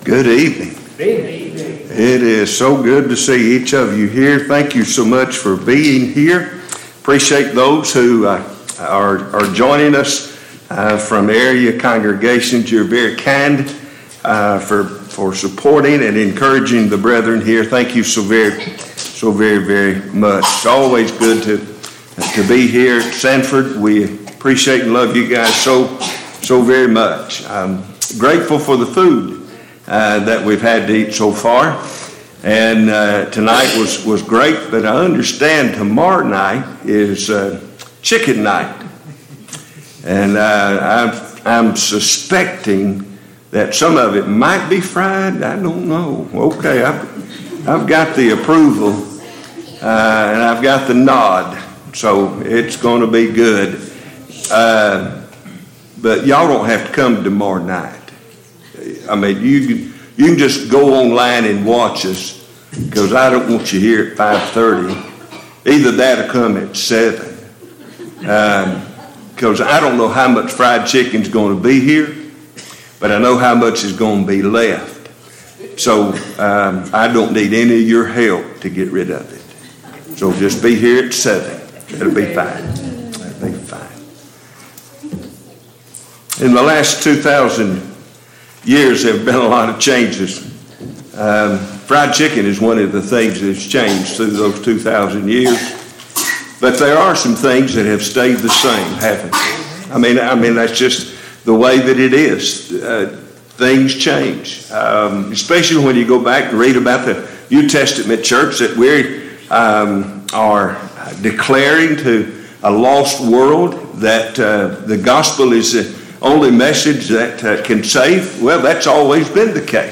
Passage: Acts 2:36-47 Service Type: Gospel Meeting